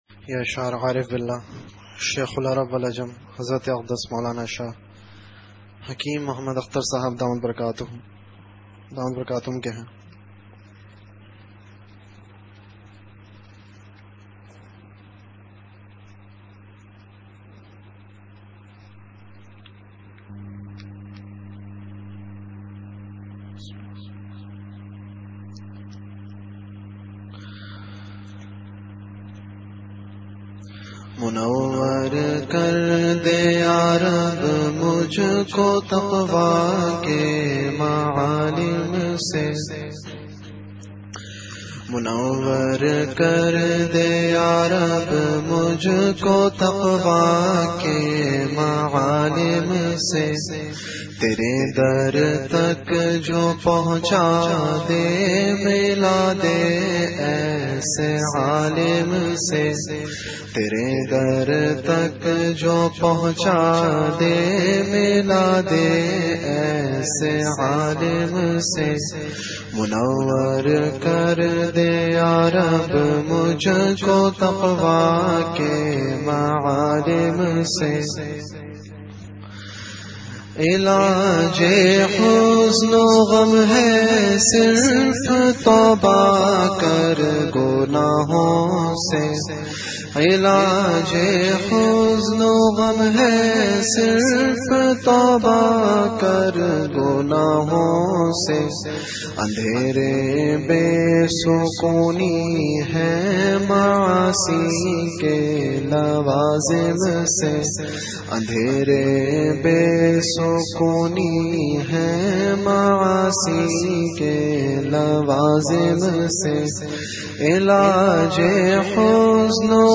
Category Majlis-e-Zikr
Venue Home Event / Time After Isha Prayer